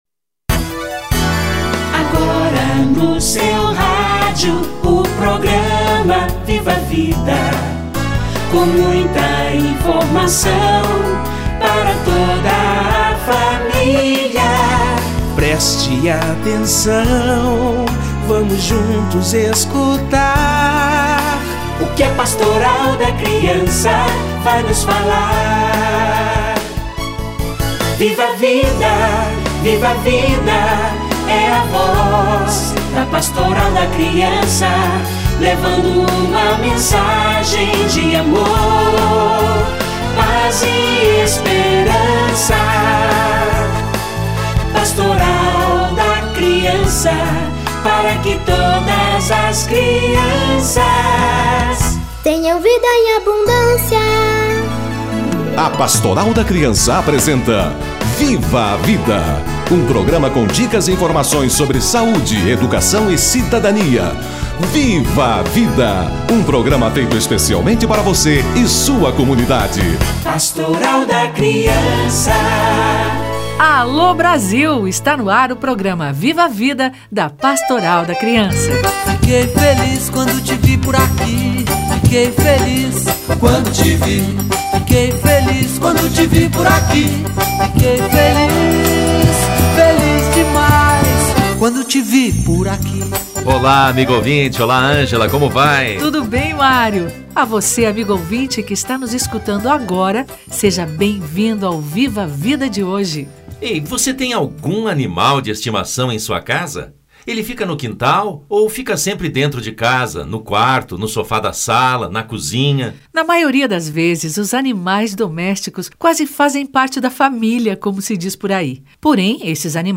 Gestantes, crianças e animais - Entrevista